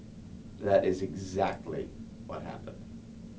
HAS-Corpus / Audio_Dataset /disgust_emotion /1072_DIS.wav